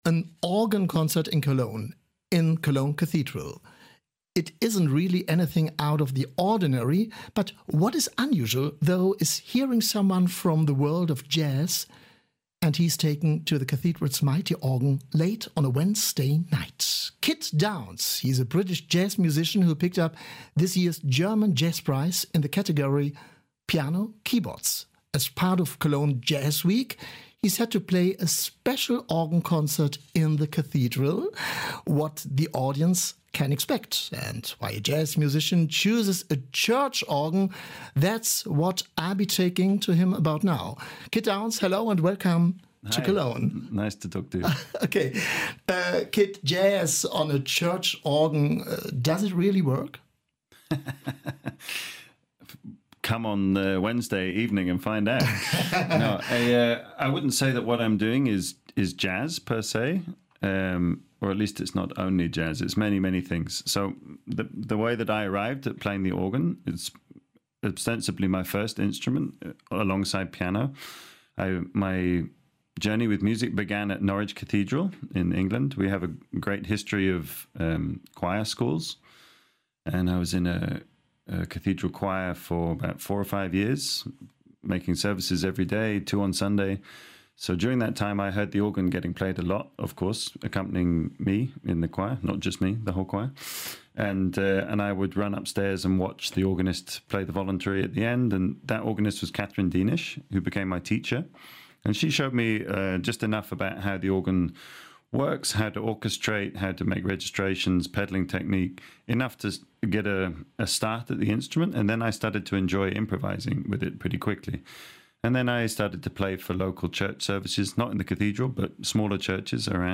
Im Interview erzählt er vorab, warum ihn dieses Instrument seit seiner Kindheit fasziniert.